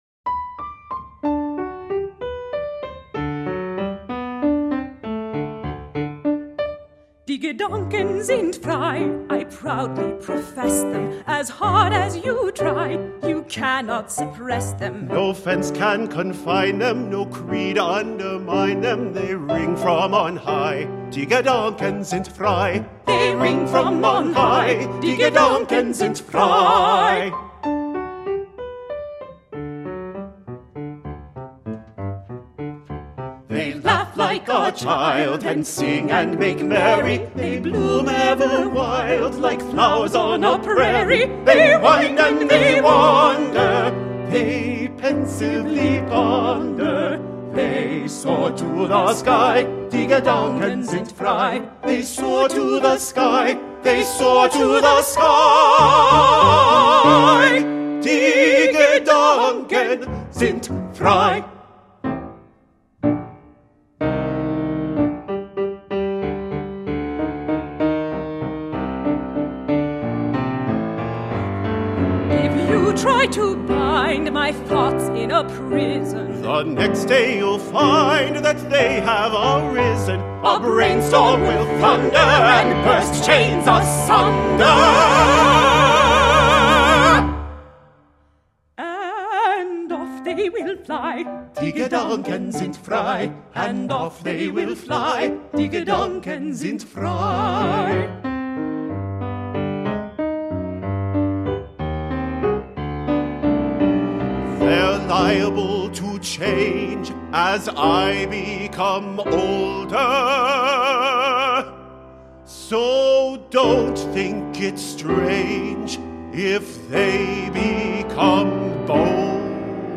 Two equal voices, piano